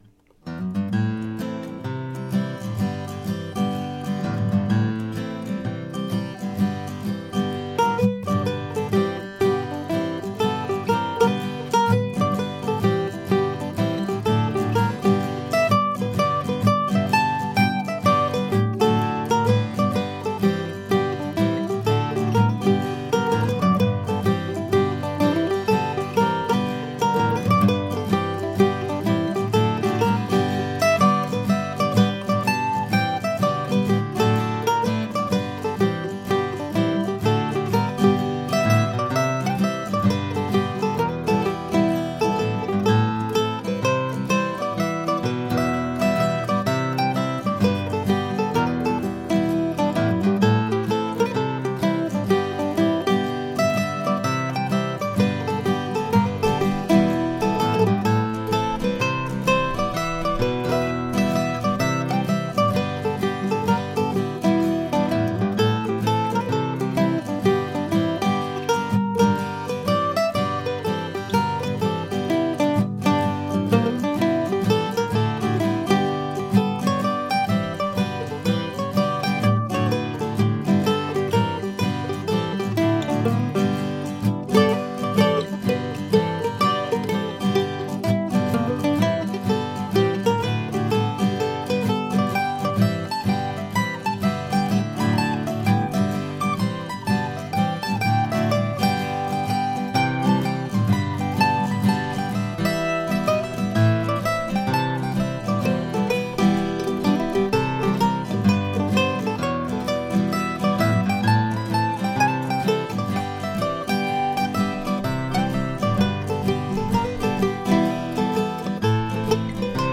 A fitting title for a sleepy little tune.